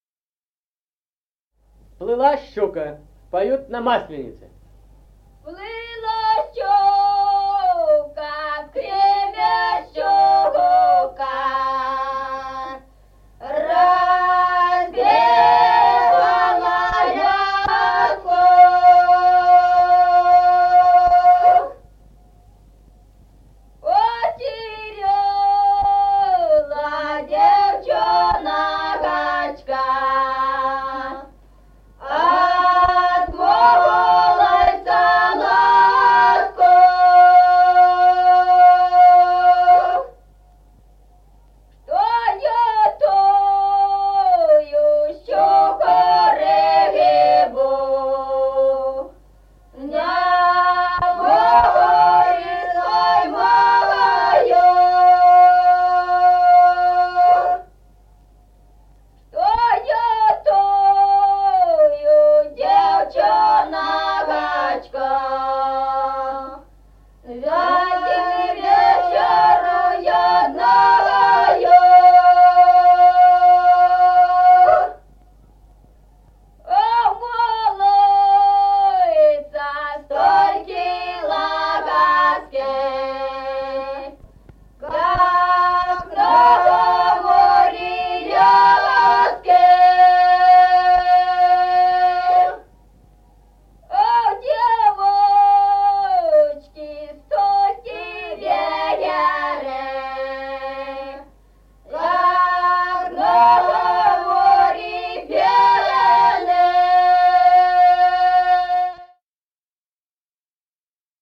Музыкальный фольклор села Мишковка «Плыла щука», масленичная.